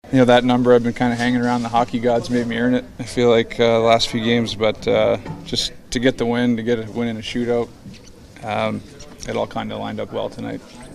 Crosby says it hasn’t been easy getting this milestone.